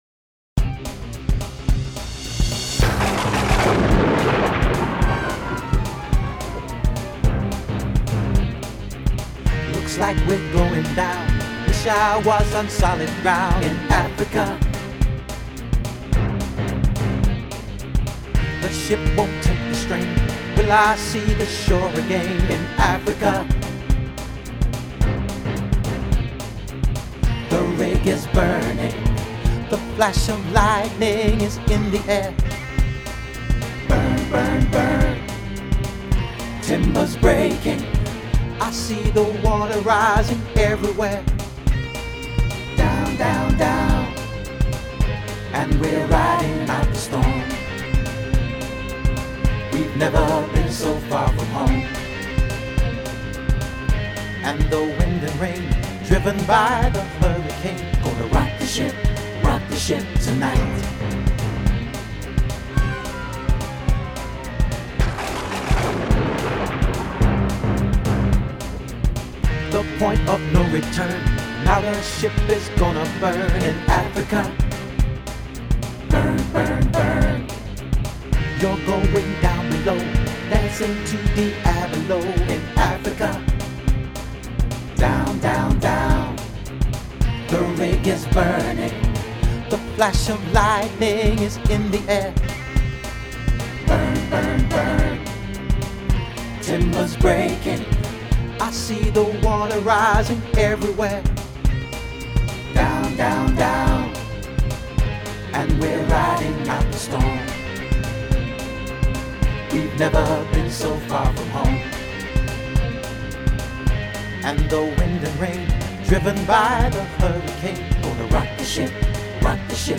full vocal